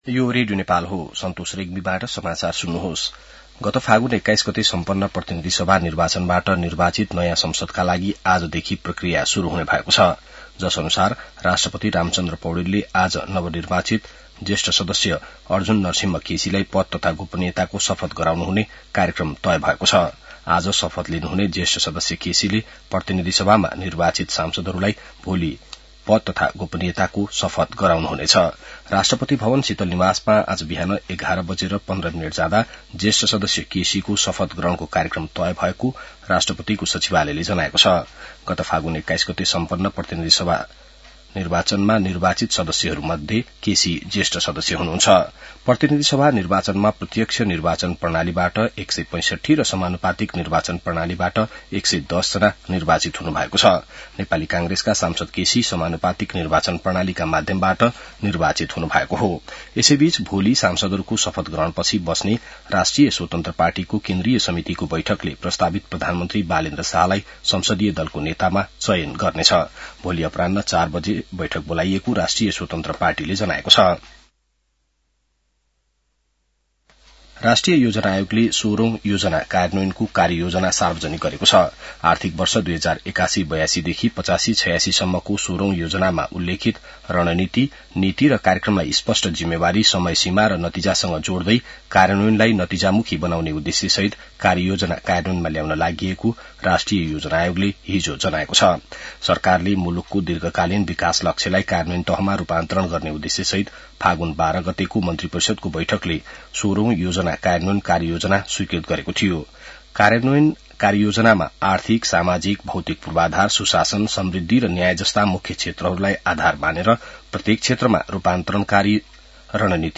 बिहान ६ बजेको नेपाली समाचार : ११ चैत , २०८२